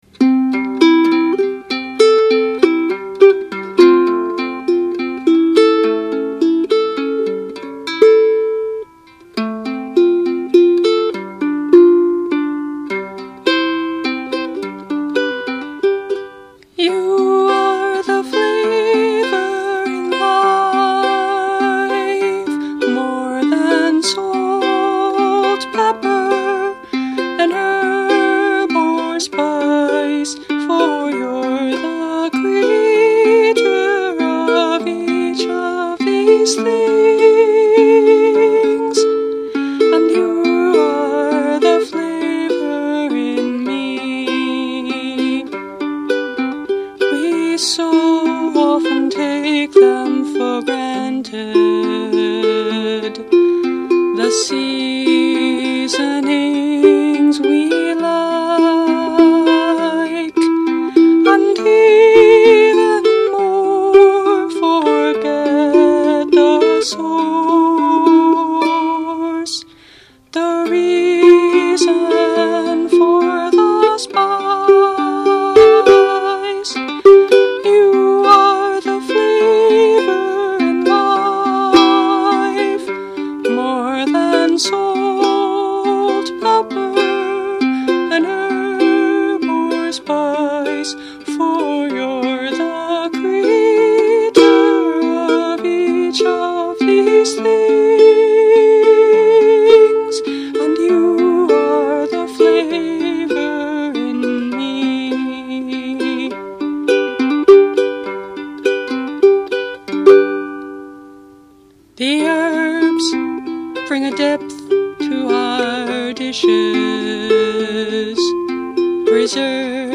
Instrument: Concert Flea ukulele